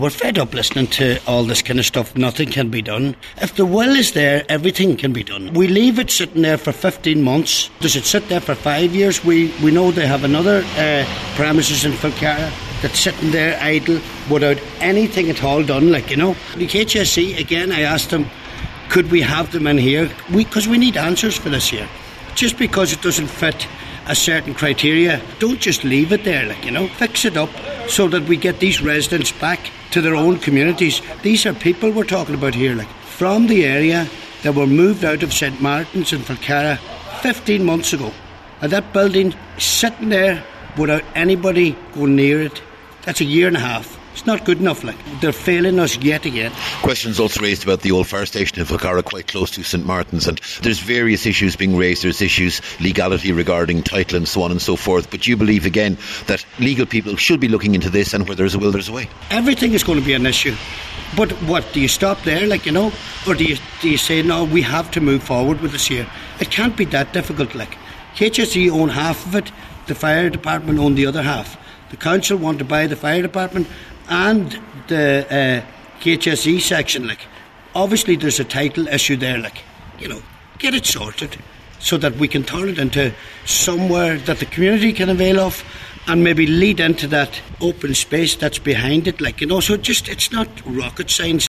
The 100% Redress Party Councillor  says ways must be found around these issues………….